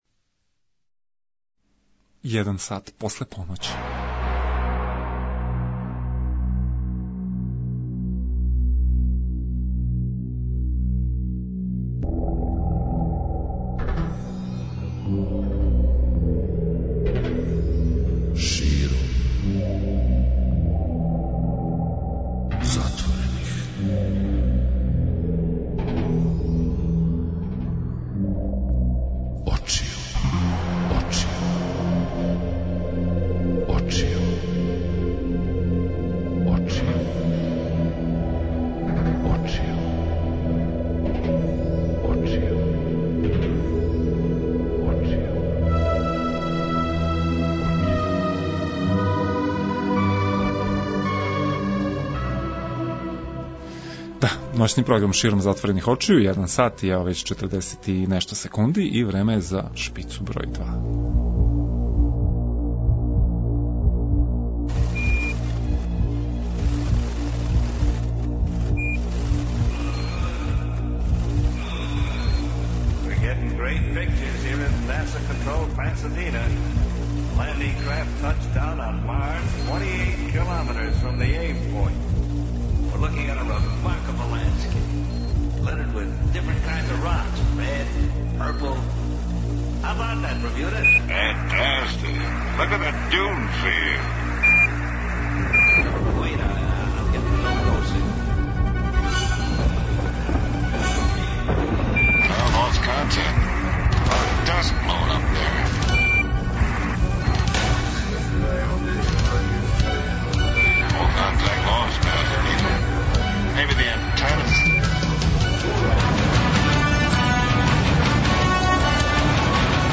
Водимо вас и на једну "вампирску" промоцију где су представљене две нове књиге - збирка прича о крвопијама Горана Скробоње "Вампирске приче" и роман "Последња кап" Драгана Јовићевића , који је био инспирисан филмом Мирослава Лакобрије "Last Drop" .